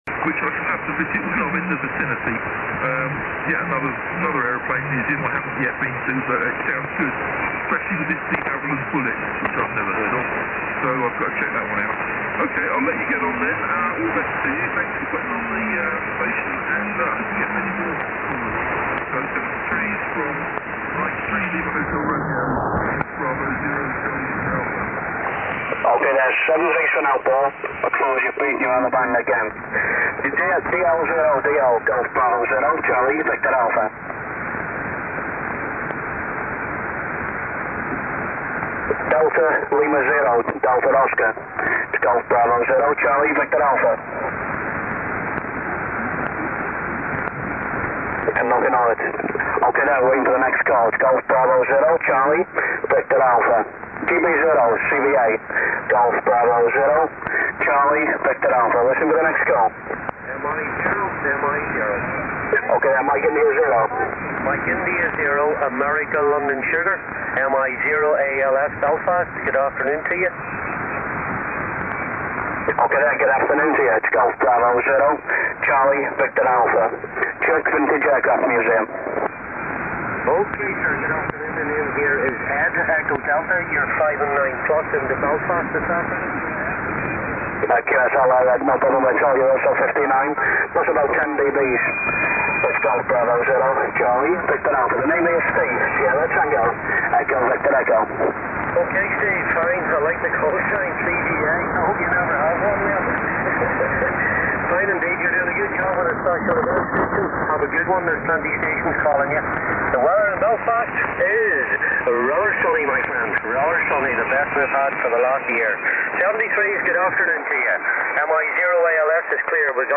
Although I was at work during the morning, When I got home, the first thing I did was switch on the receiver, attached my MD recorder to it, and grab a few clips!
Equipment Used: Icom IC-R75 RX (Vanilla), 10 ft longwire round room, Sony MZ-R55 MiniDisc Recorder.
Cleaned up Using Cool-Edit, and encoded with VBR using the in-built encoder !